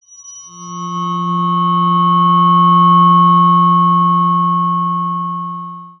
174Hz Unweighted Aluminium Tuning Fork
Handcrafted 174Hz Unweighted Tuning Fork made from high-grade aluminium, providing a long and enduring tone.
The 174Hz is the lowest frequency in the 9 solfeggios.
174Hz-Tuning-fork.mp3